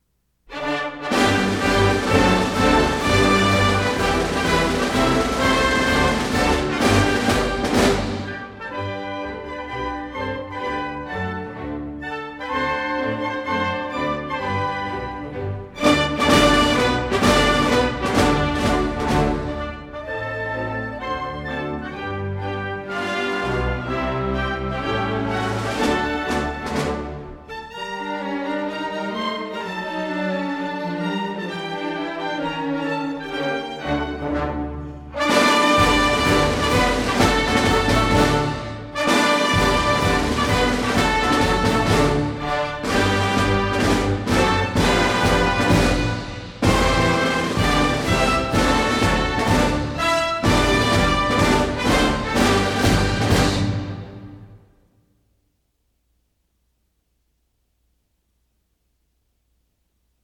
法国国歌《马赛曲》 伯恩斯坦指挥 纽约爱乐乐团 法国自１８８０年至今，在７月１４日举行国庆游行时必奏《马赛曲》。